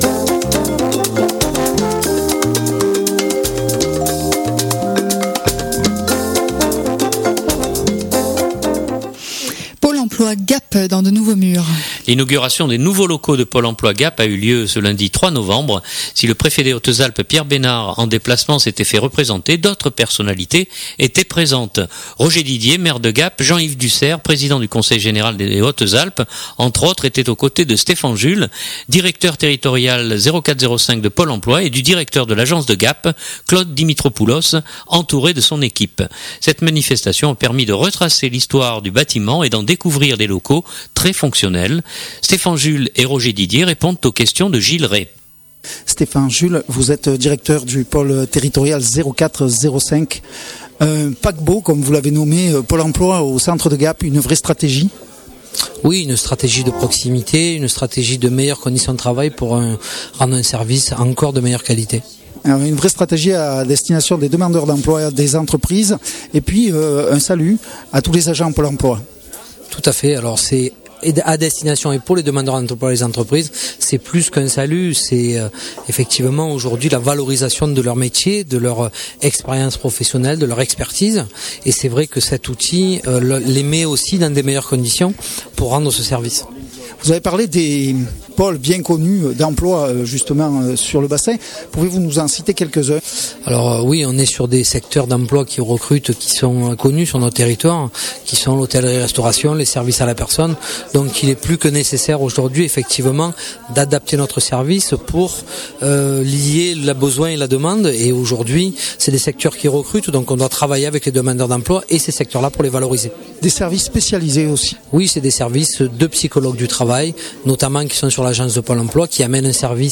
L'inauguration des nouveaux locaux de Pôle emploi Gap a eu lieu ce lundi 3 novembre.